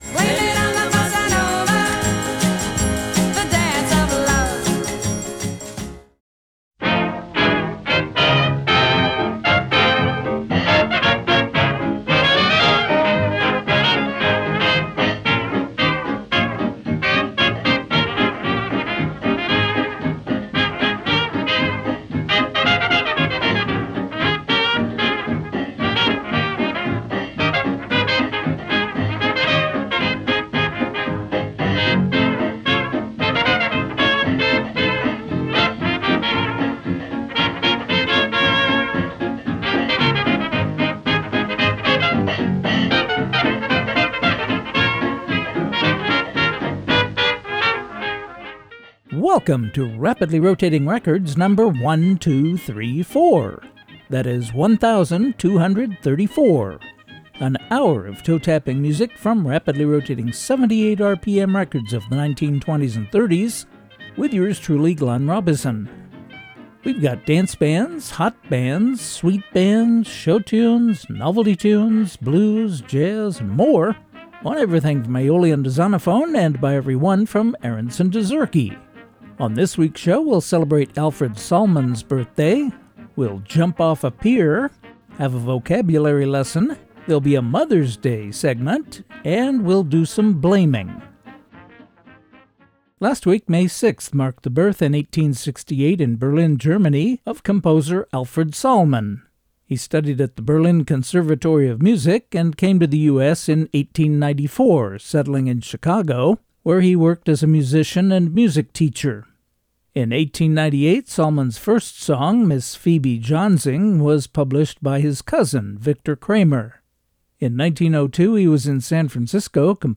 bringing you vintage music to which you can’t *not* tap your toes, from rapidly rotating 78 RPM records of the 1920s and ’30s.